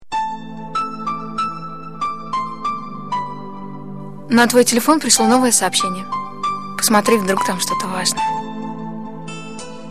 Girl_Vaznoe_sms.mp3